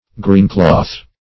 Greencloth \Green"cloth`\ (-kl[o^]th`; 115), n.